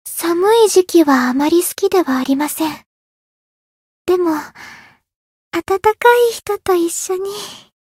灵魂潮汐-爱莉莎-圣诞节（摸头语音）.ogg